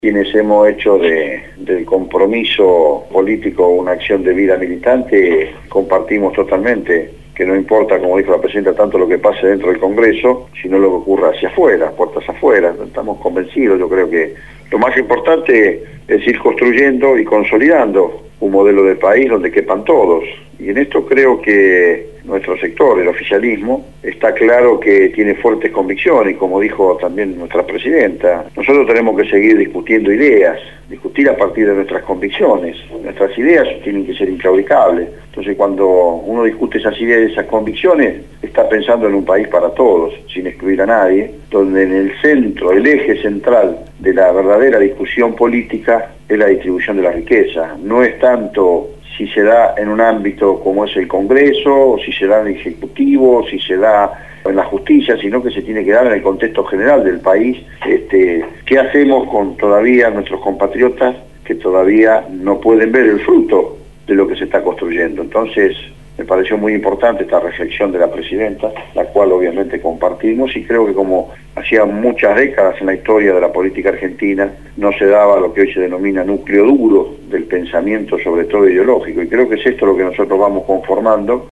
En «Punto de Partida» (Lunes a Jueves, de 8 a 10hs), fue entrevistado el Diputado Nacional del FPV y Secretario General del Gremio de los Canillitas Omar Plaini.